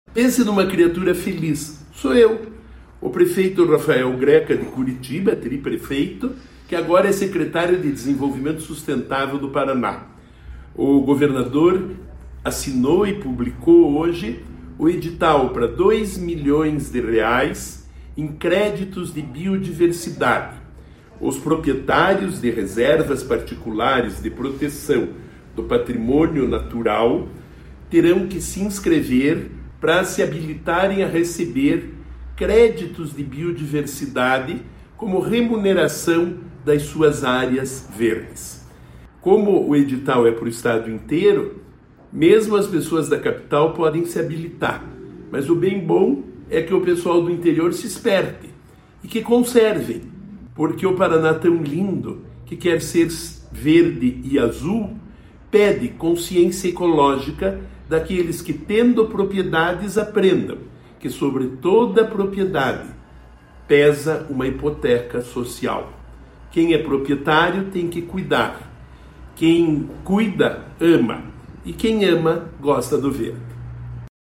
Sonora do secretário do Desenvolvimento Sustentável, Rafael Greca, sobre os créditos de biodiversidade no Paraná